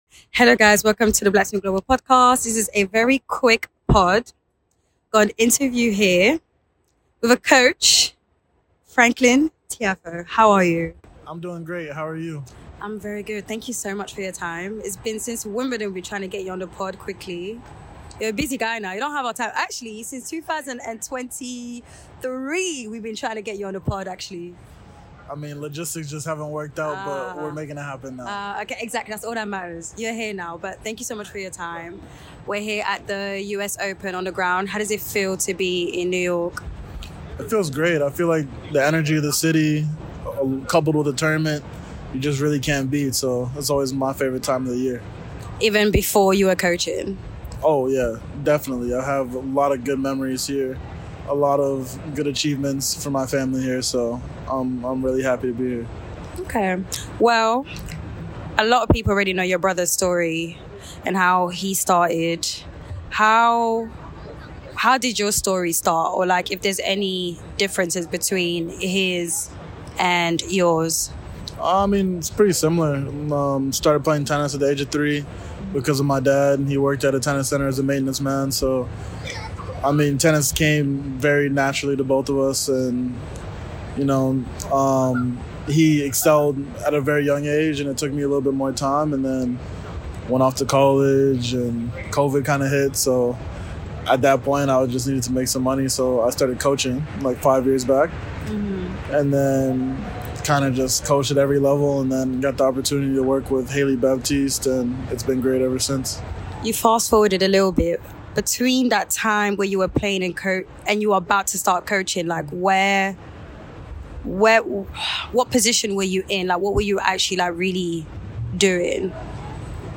Black Spin Global: The Podcast Chat